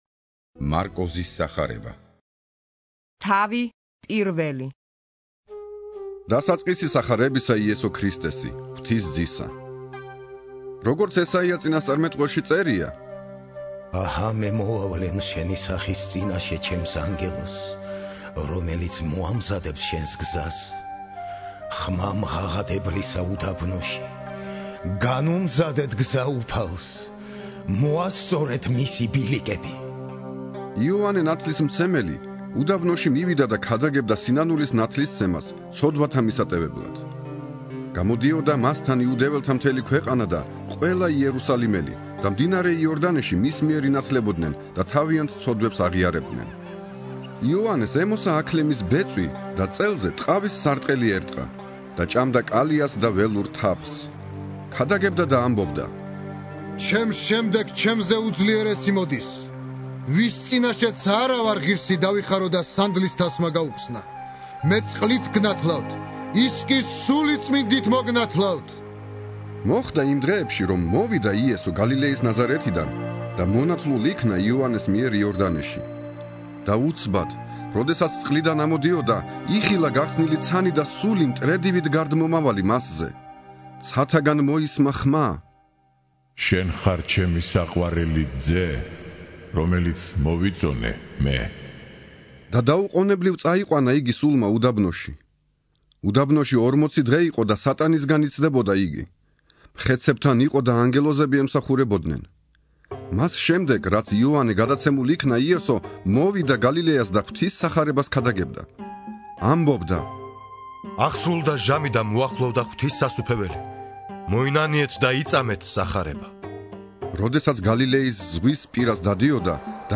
(41) ინსცენირებული ახალი აღთქმა - მარკოზის სახარება